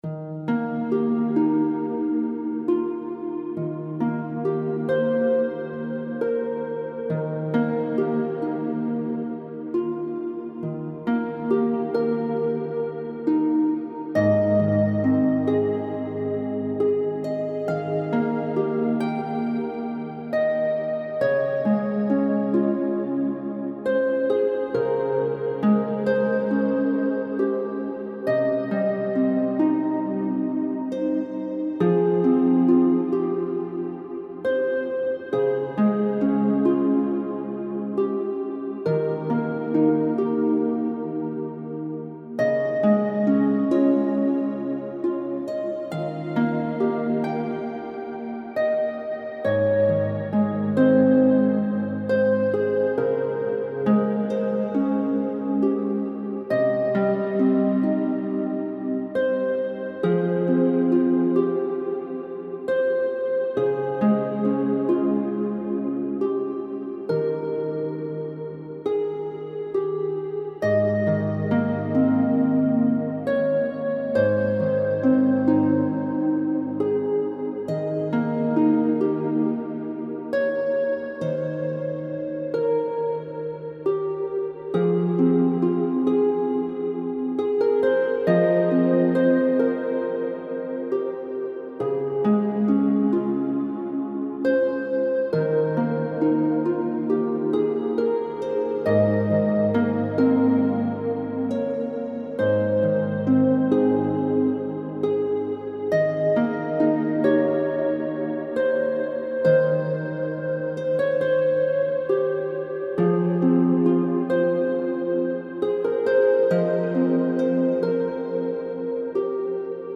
Heavenly Harp Music